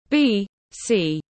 Trước công nguyên tiếng anh gọi là B.C, phiên âm tiếng anh đọc là /ˌbiːˈsiː/
B.C /ˌbiːˈsiː/